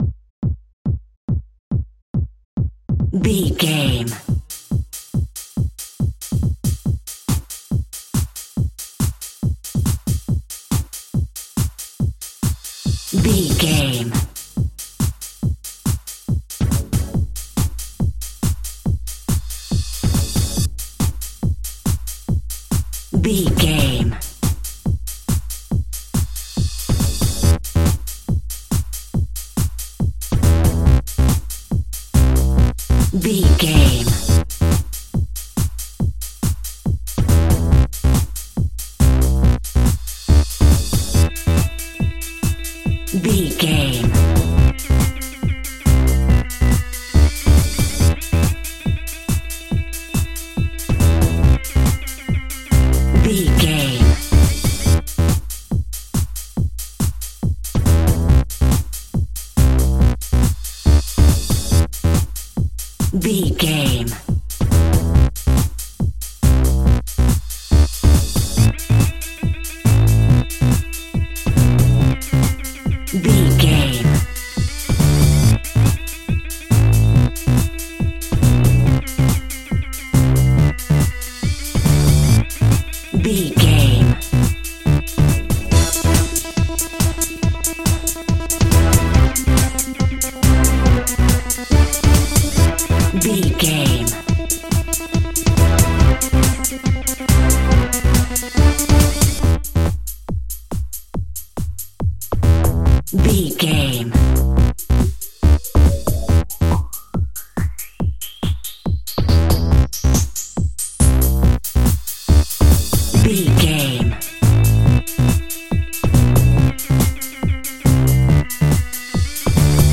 Fast paced
Aeolian/Minor
Fast
aggressive
powerful
dark
funky
groovy
driving
energetic
synthesiser
drum machine
techno
acid house music